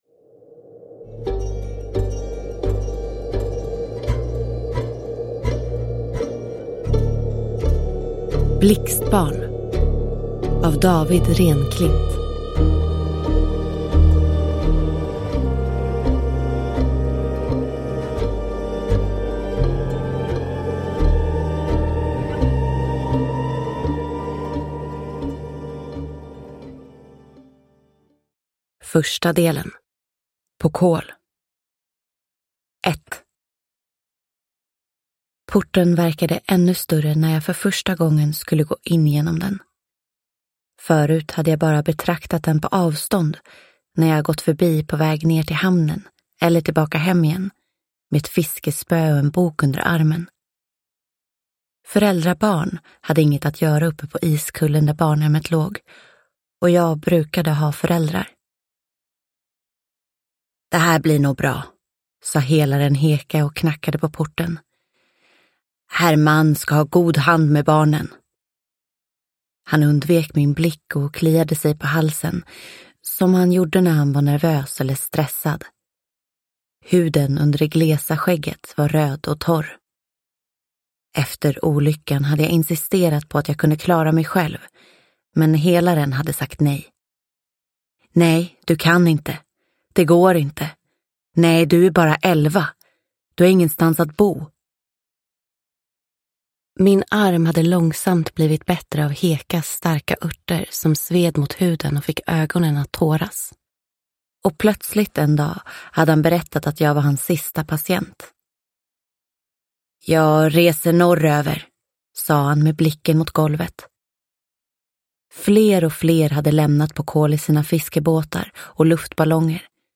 Blixtbarn – Ljudbok – Laddas ner